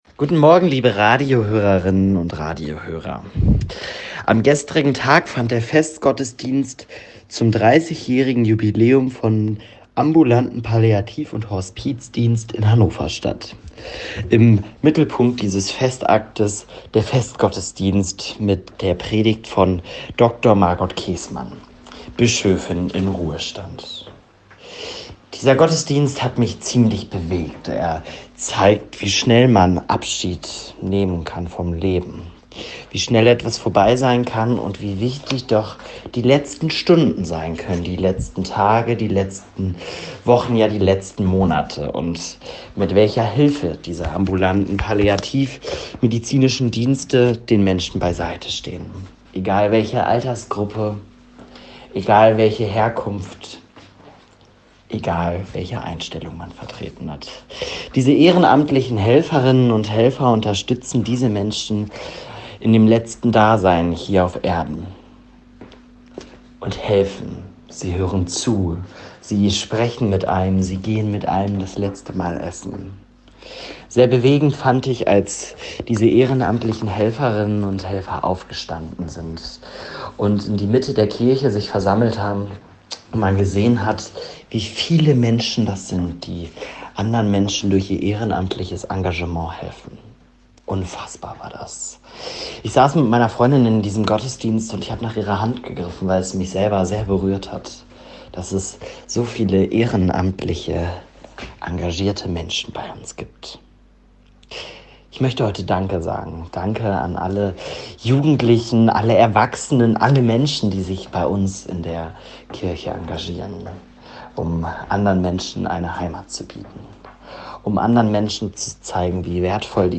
Radioandacht vom 8. Mai